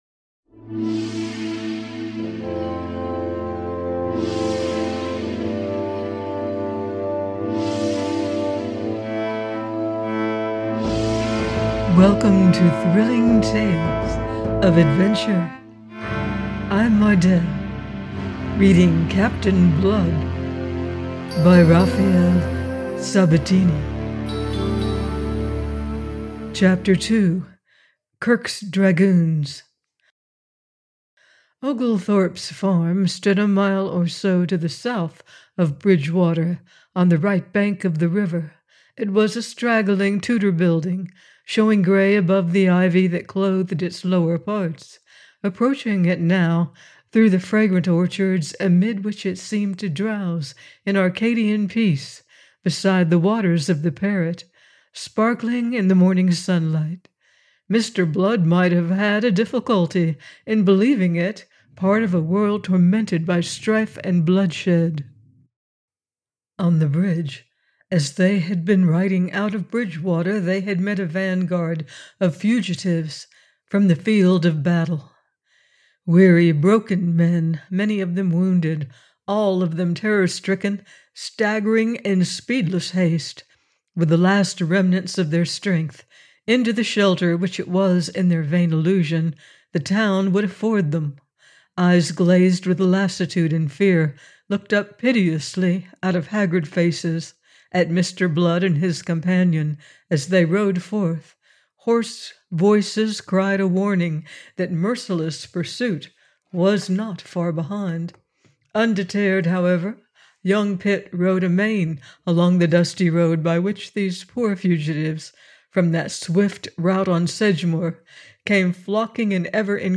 Captain Blood – by Raphael Sabatini - audiobook